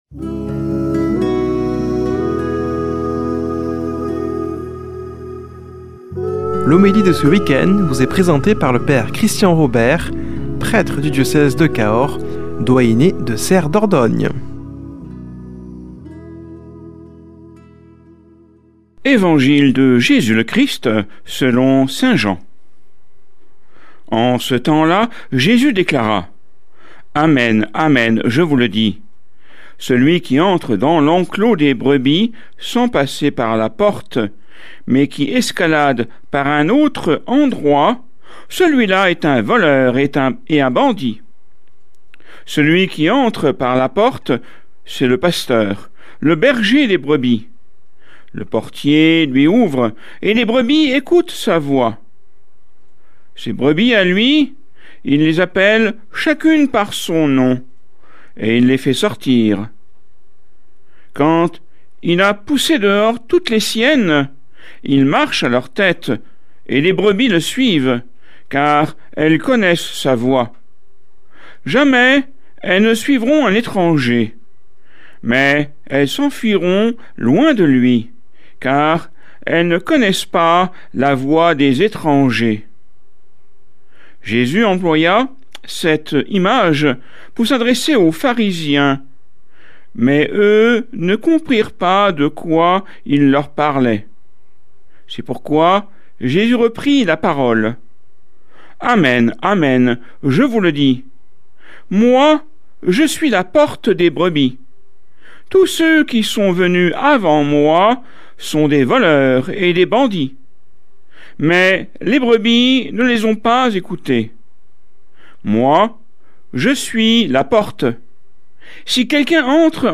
Homélie du 25 avr.